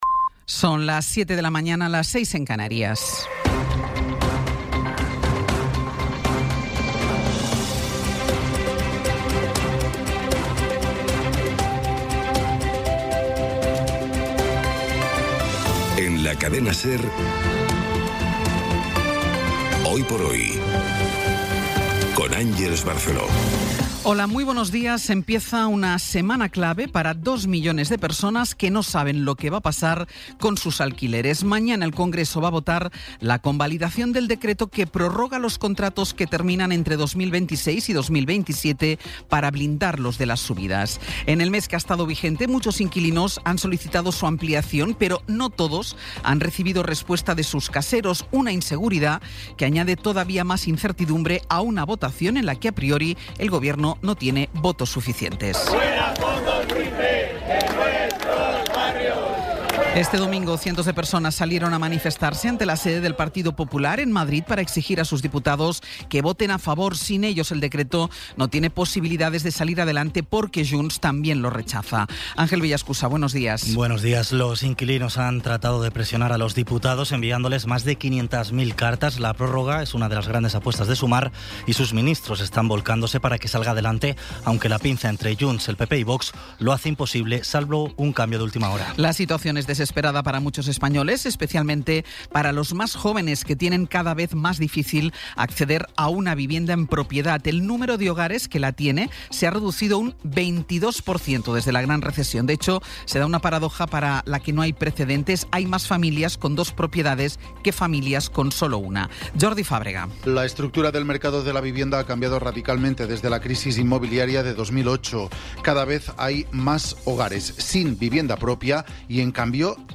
Resumen informativo con las noticias más destacadas del 27 de abril de 2026 a las siete de la mañana.